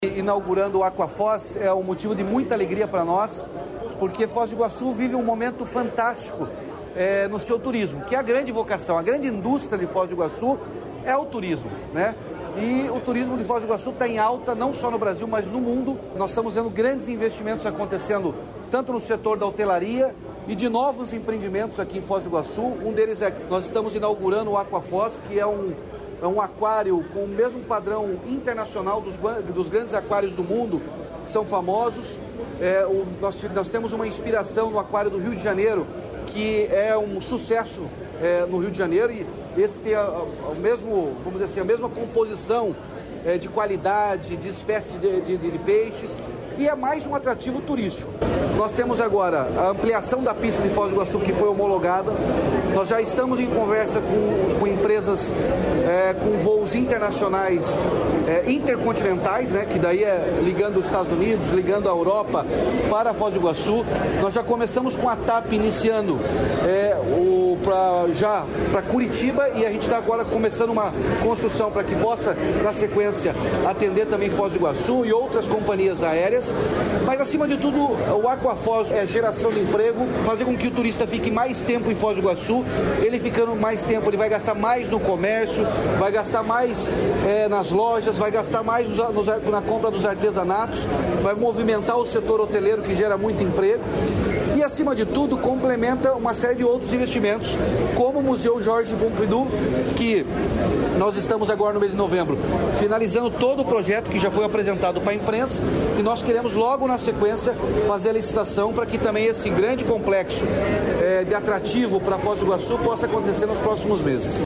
Sonora do governador Ratinho Junior sobre o novo aquário de Foz do Iguaçu